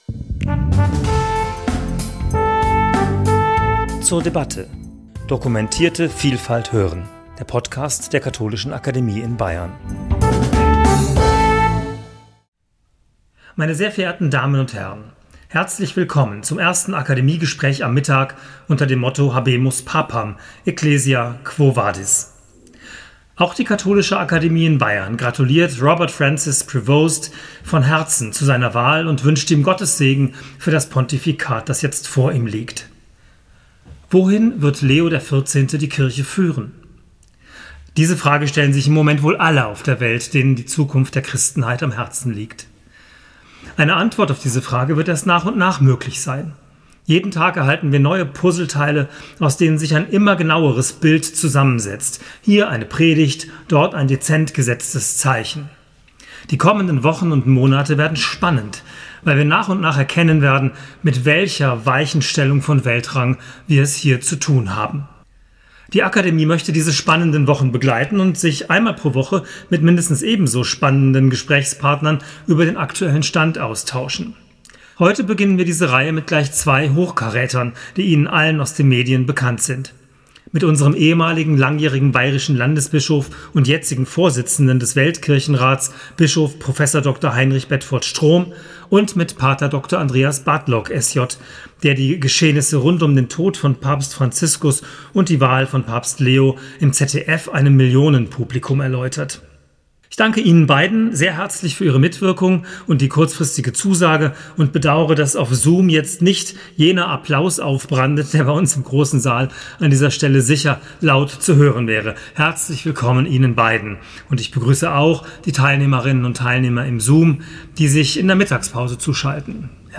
Gespräch zum Thema 'Habemus papam! Ecclesia, quo vadis?', Folge 1 ~ zur debatte Podcast